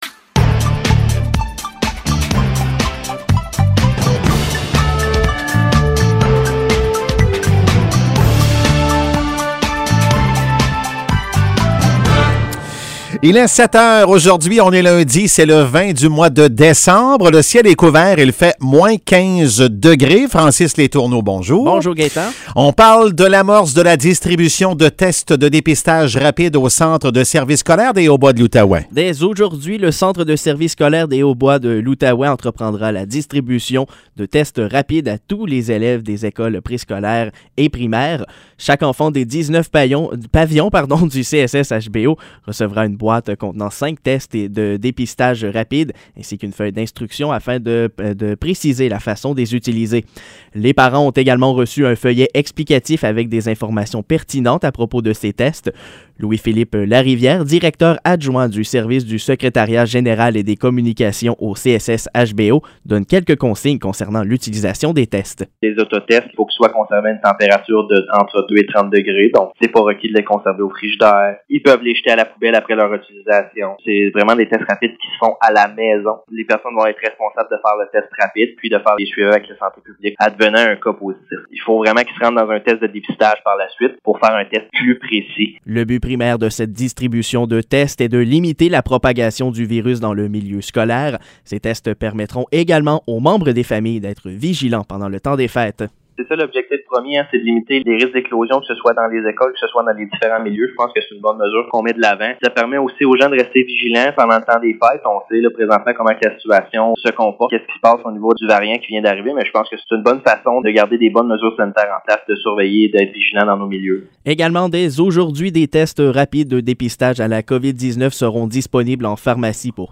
Nouvelles locales - 20 décembre 2021 - 7 h